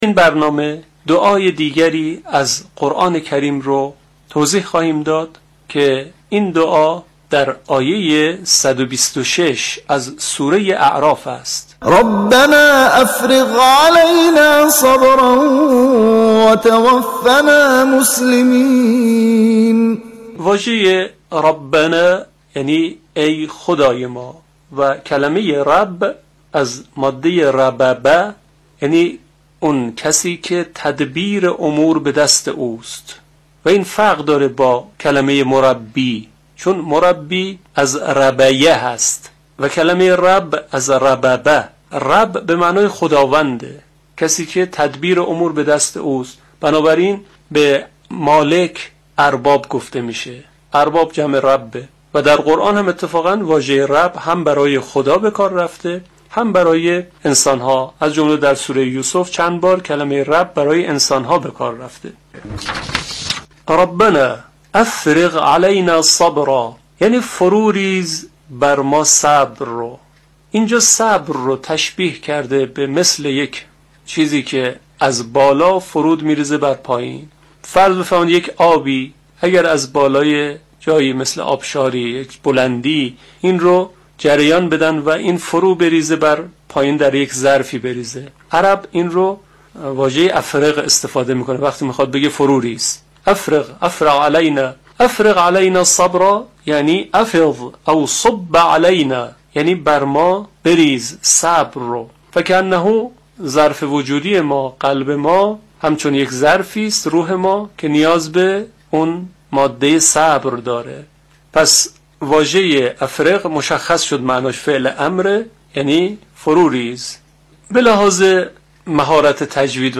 برنامه رادیویی «قنوت آیه‌ها» با هدف شرح و تفسیر آیه‌های قرآنی که در قنوت نماز خوانده می‌شود، به روی آنتن رادیو قرآن می‌رود.
این برنامه شامگاه گذشته، هفتم اسفندماه به شرح واژگان و مفردات بخش پایانی آیه 126 سوره مبارکه اعراف «...رَبَّنا أَفْرِغْ عَلَيْنا صَبْراً وَ تَوَفَّنا مُسْلِمينَ» پرداخت. همچنین پخش فرازهایی از تلاوت قاریان شهیر از دیگر بخش این برنامه است.